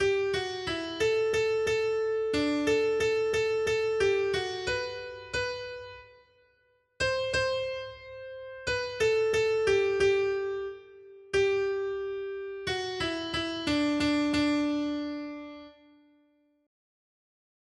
Noty Štítky, zpěvníky ol306.pdf responsoriální žalm Žaltář (Olejník) 306 Skrýt akordy R: Hle, ženich přichází, jděte naproti Kristu Pánu! 1.